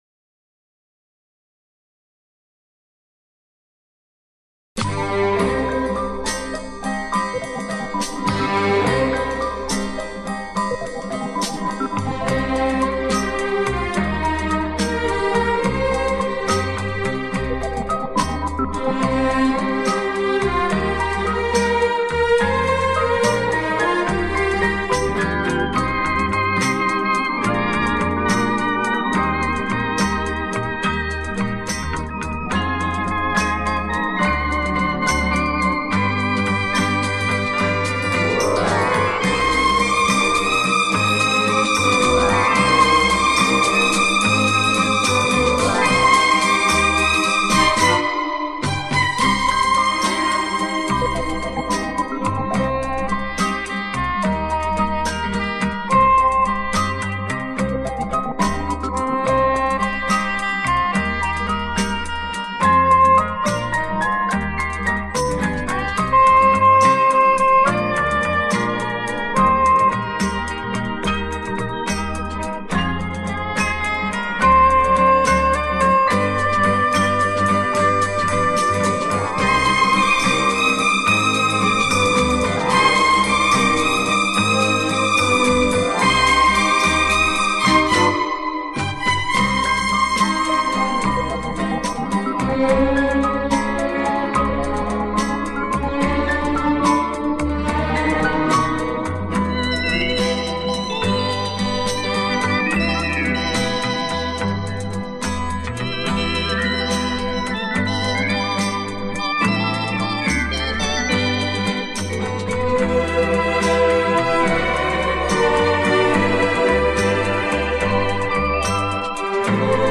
las notas de un órgano Hammond de los setenta susurrando